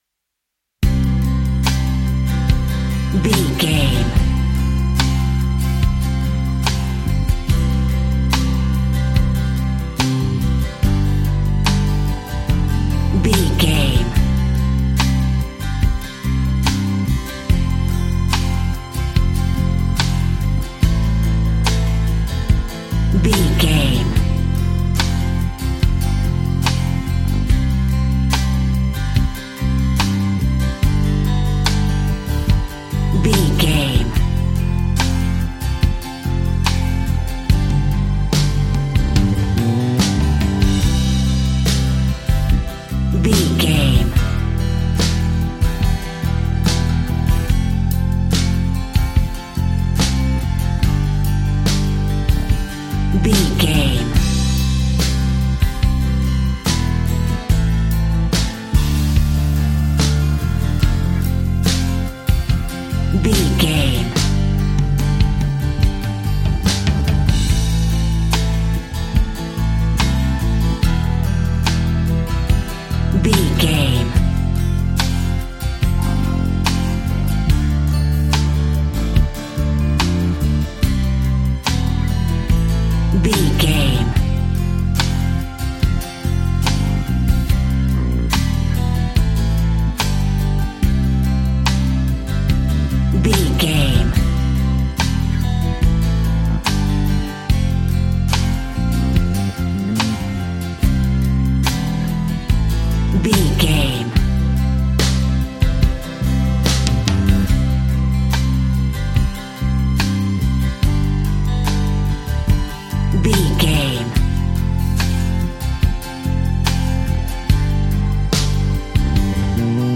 Ionian/Major
sweet
acoustic guitar
bass guitar
drums